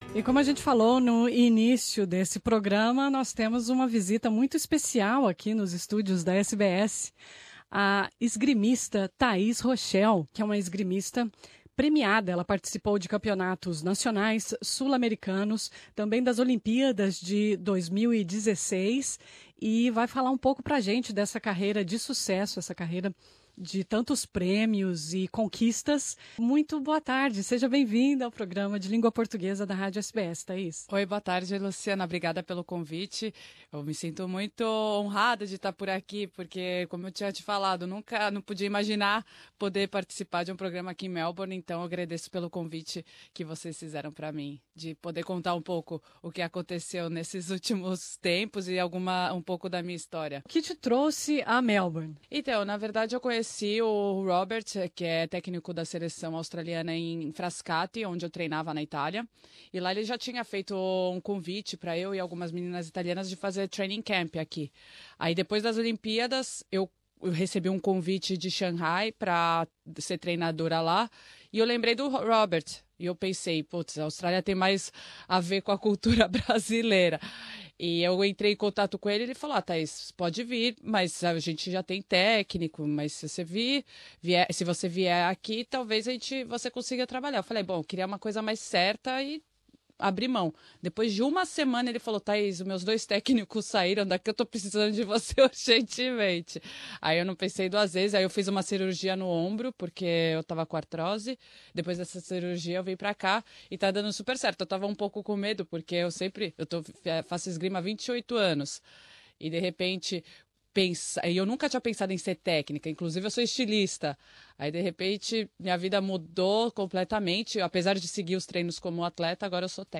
Nessa entrevista ela fala de seu treinamento de atleta, planos para o futuro e porque trocou São Paulo por Melbourne.